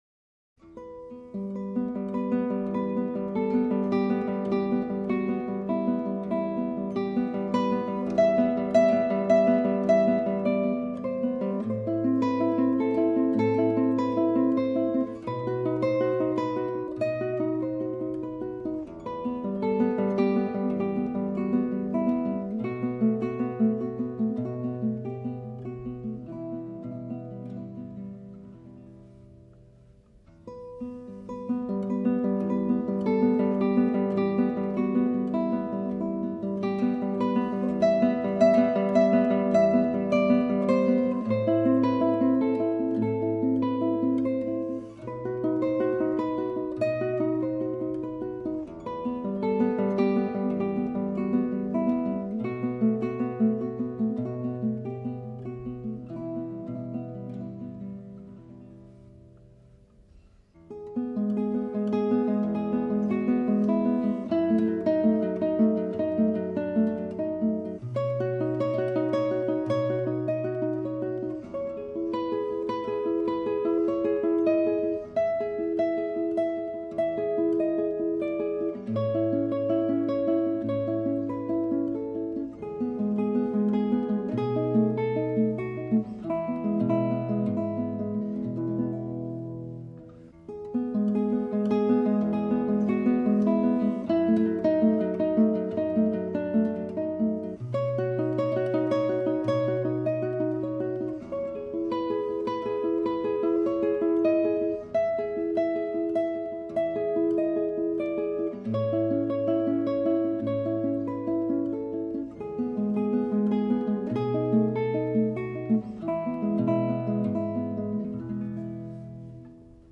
随着电影的上映，这只曲子开始广为流传，乐曲优美纯朴的旋律与清澈的分解和弦完全溶和成一体，给人以极深的印象。